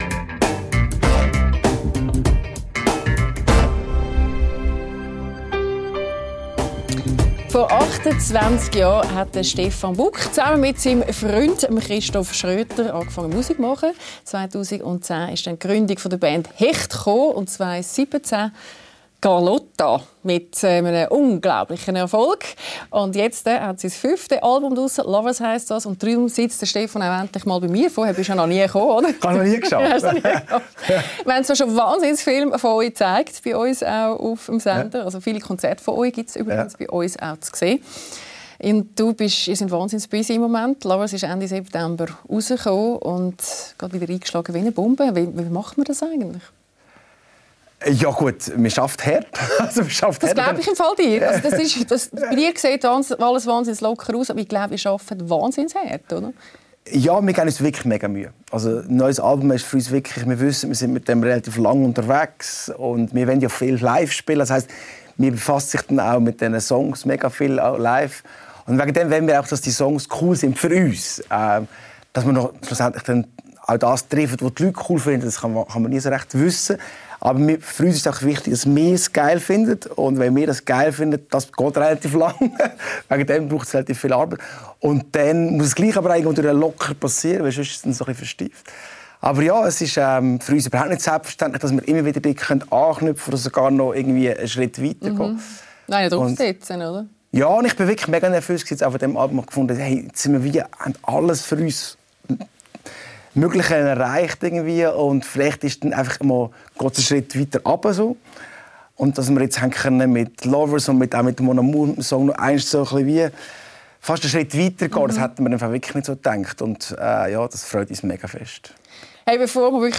Ein ehrliches Gespräch über Musik, Liebe und das Leben zwischen zwei Welten.